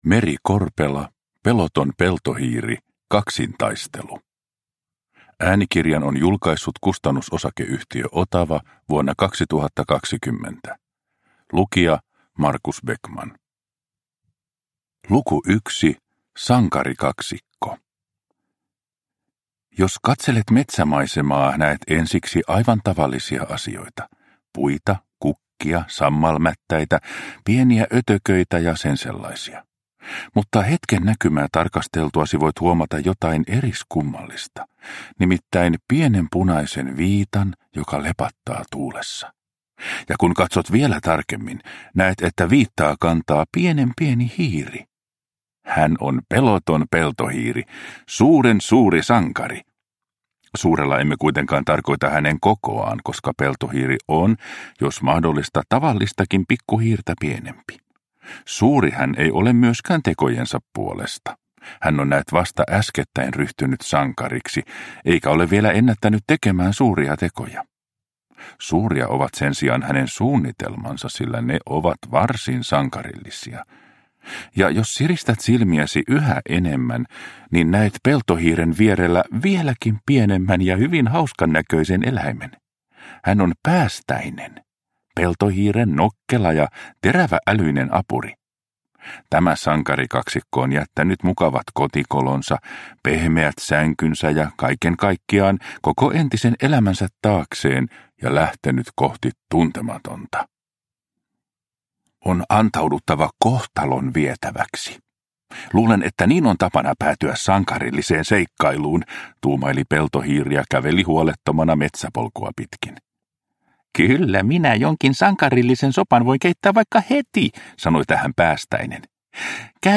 Peloton Peltohiiri - Kaksintaistelu – Ljudbok – Laddas ner